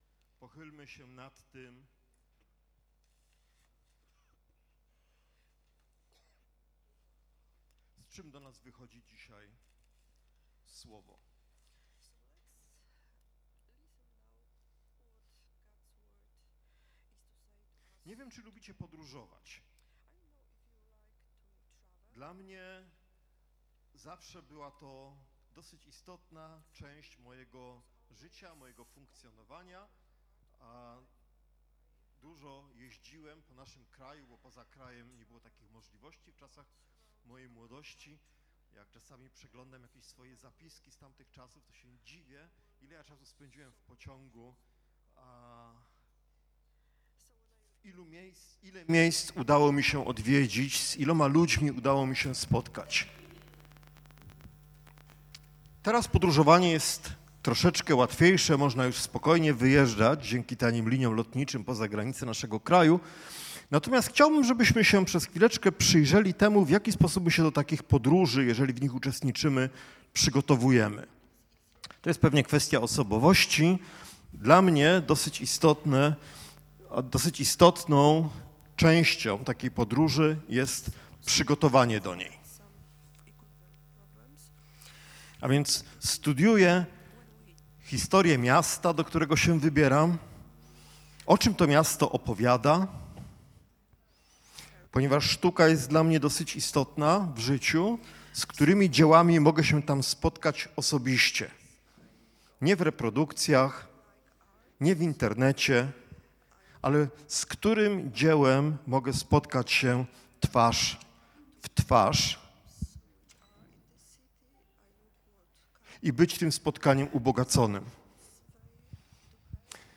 Rdz 2-3 Rodzaj Usługi: Nauczanie niedzielne Tematy